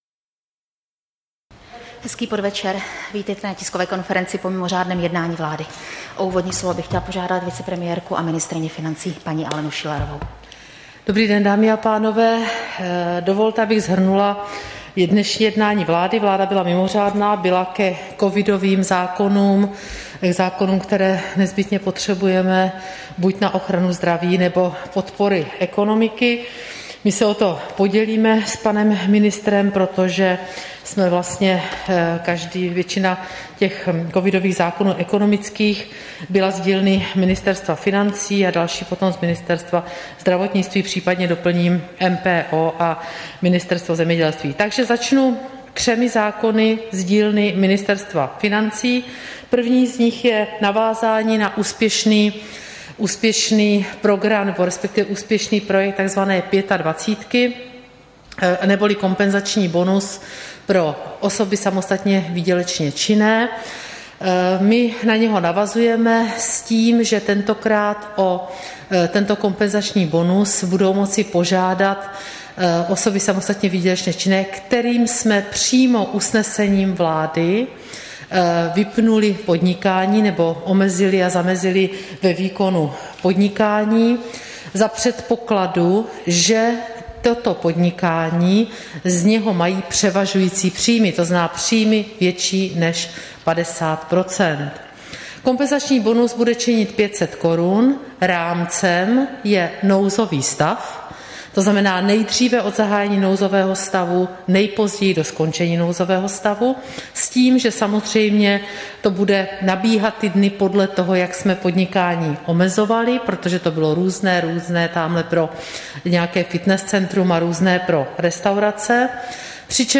Tisková konference po mimořádném jednání vlády, 16. října 2020